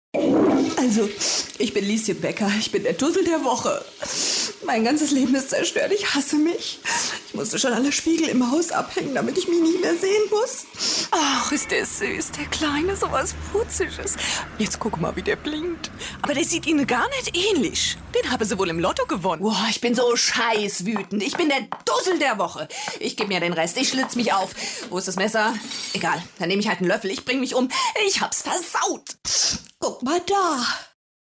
Mittel plus (35-65)